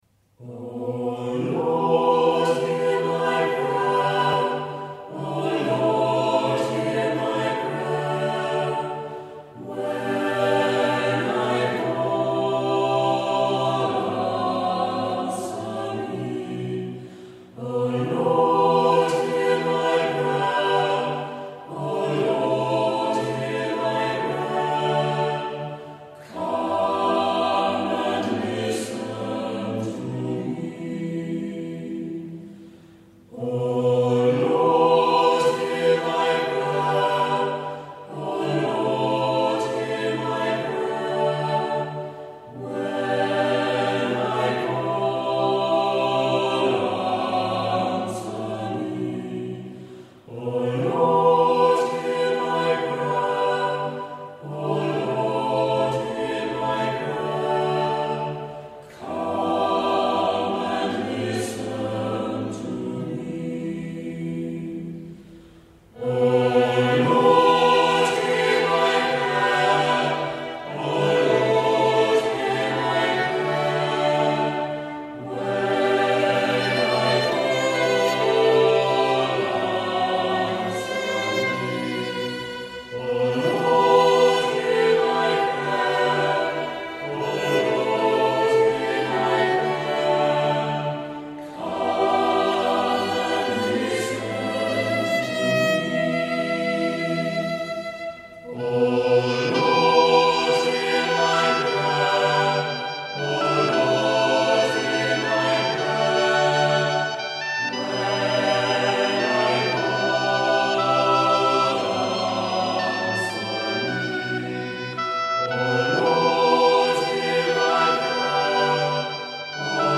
Het gaat deze zondag om het bidden. Daarom vandaag zeven muzikale gebeden op toon gezet door bekende en onbekende componisten door de eeuwen heen.